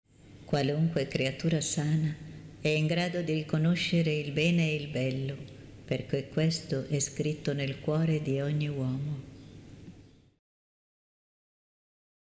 preghiera mp3 –